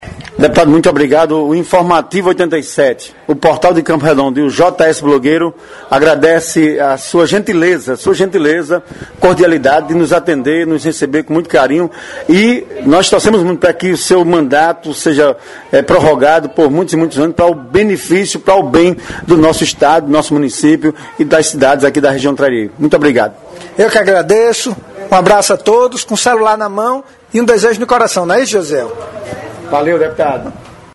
ENTREVISTA_COM_O_DEPUTADO_ESTADUAL_KELPS_LIMA___CONSIDERACOES_FINAIS.mp3